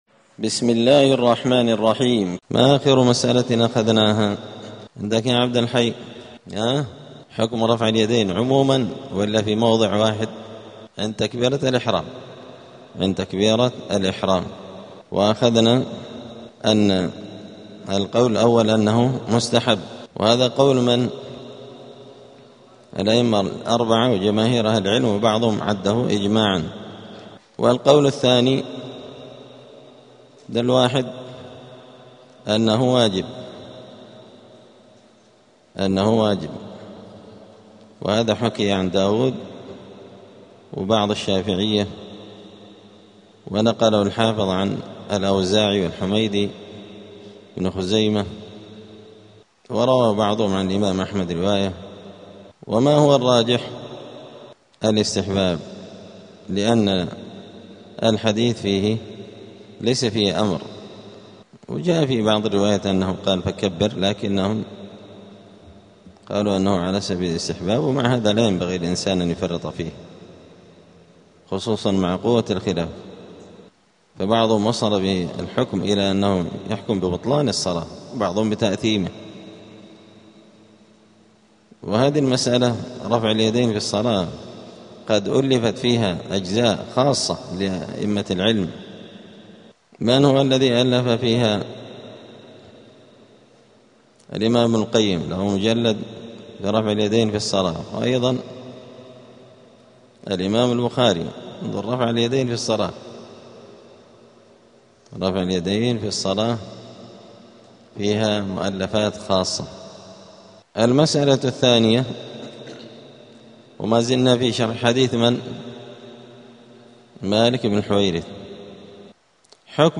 دار الحديث السلفية بمسجد الفرقان قشن المهرة اليمن
*الدرس التاسع والسبعون بعد المائة [179] باب صفة الصلاة {حكم رفع اليدين عند الركوع وعند الرفع منه}*